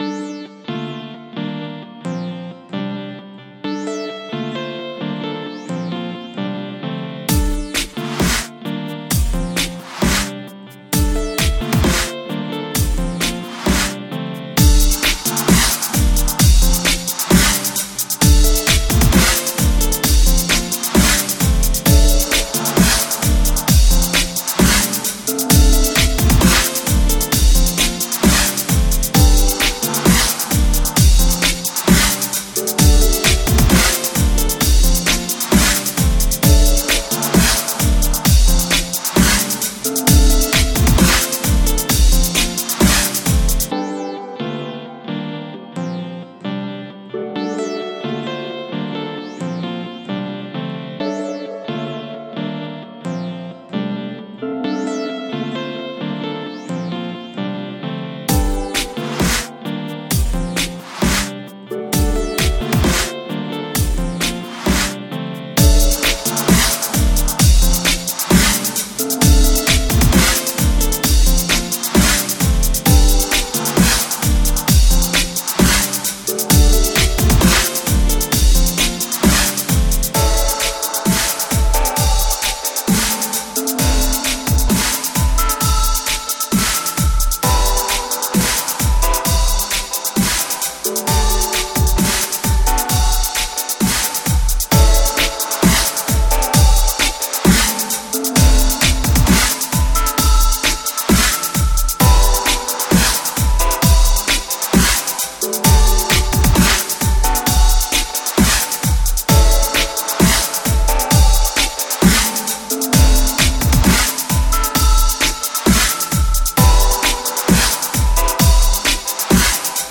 glitch/Dubstep/DnB/TripHop and basically electronica projekt